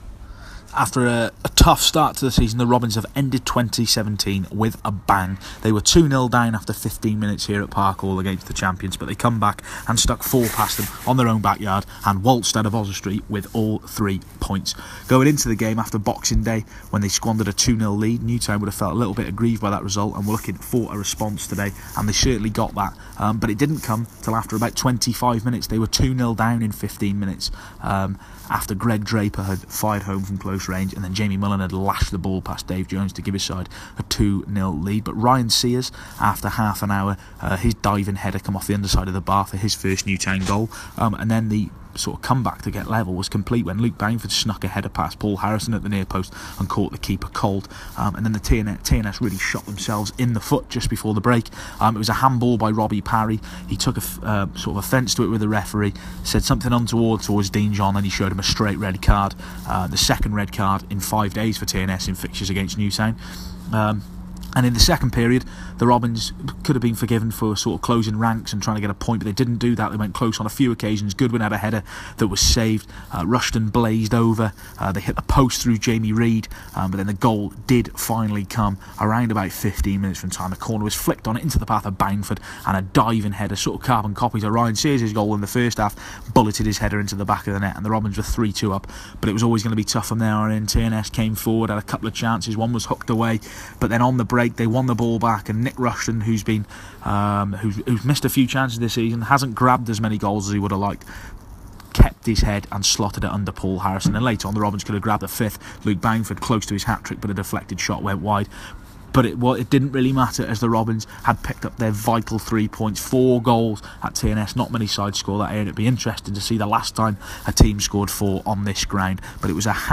AUDIO REPORT l TNS 2-4 Robins